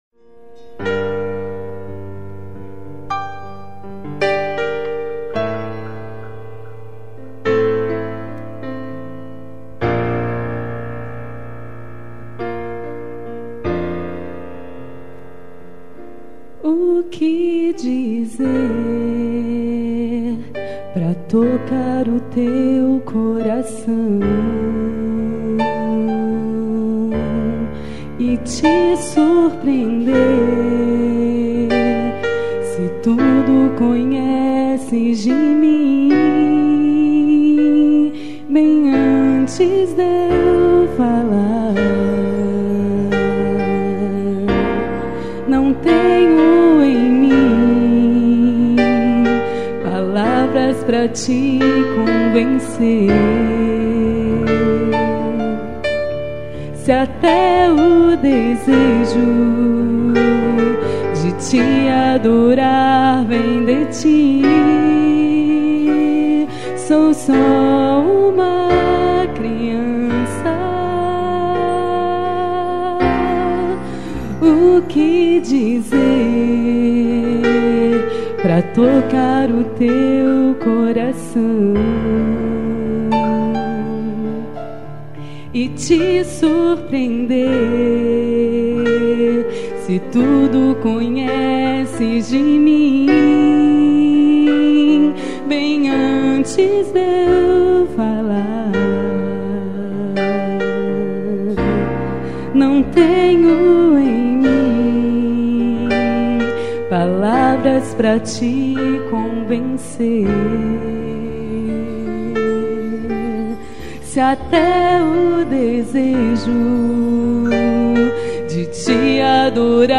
Bateria
Contra-baixo
Violão
Guitarra
Teclados
Percussão
Saxofone
Vocal: